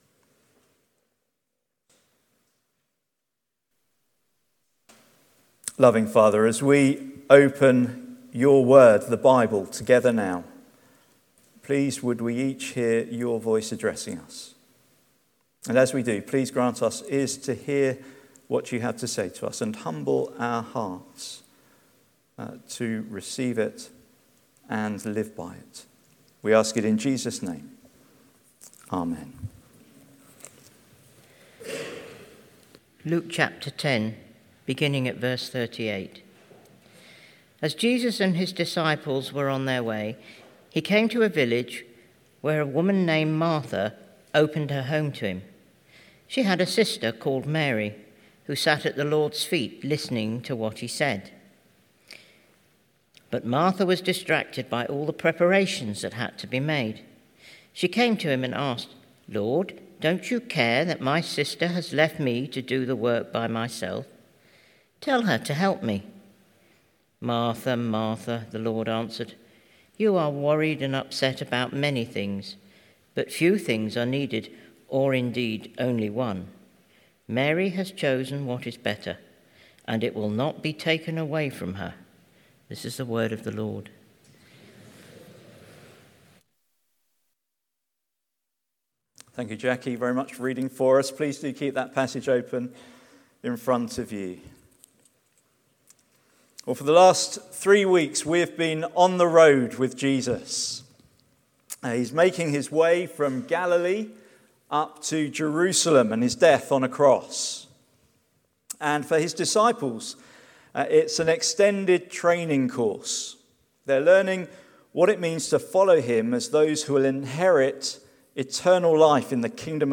Sermon Transcript